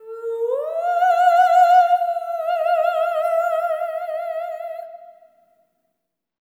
ETHEREAL01-L.wav